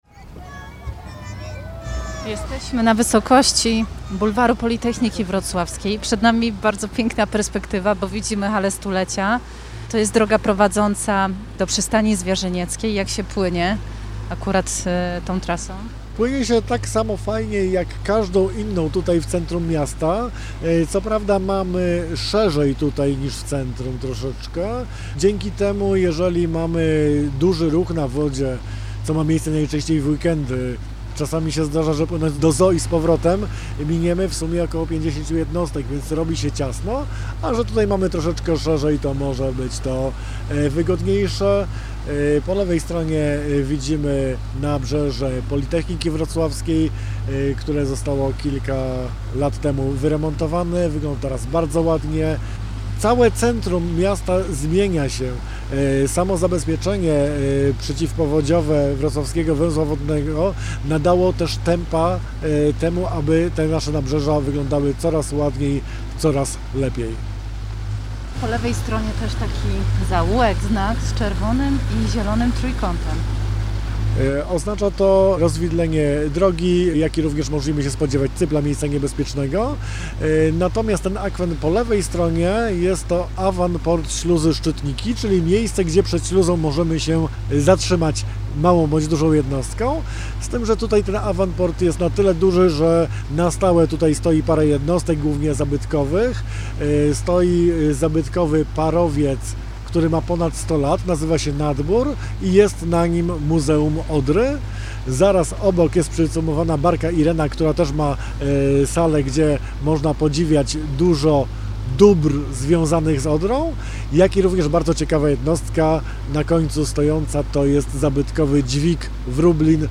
Gość programu: